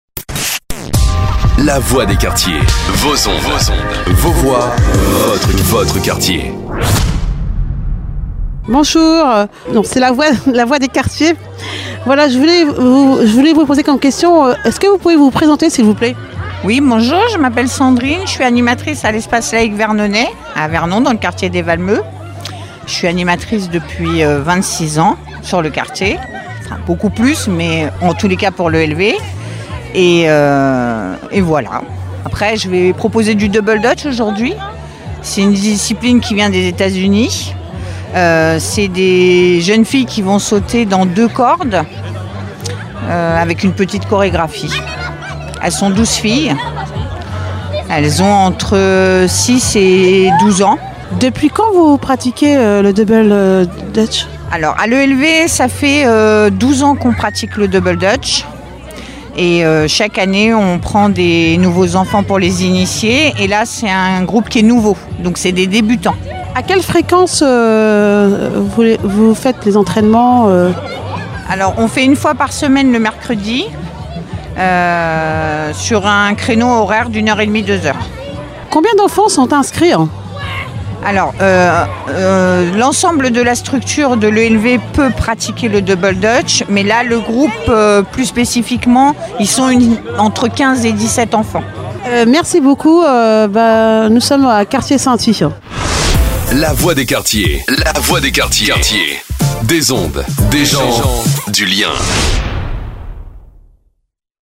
VERNON SCINTILLE DANS LES QUARTIERS INTERVIEW ELV DOUBLE DUTCH